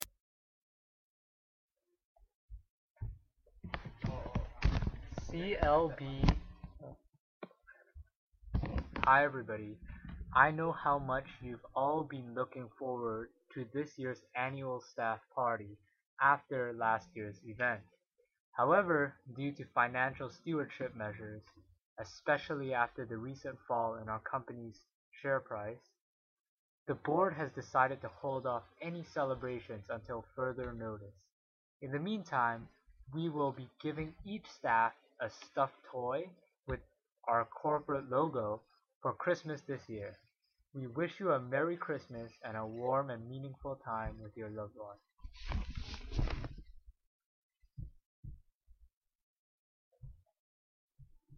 CLB6L Listen to a manager apologize to employees for having to cancel the annual staff party.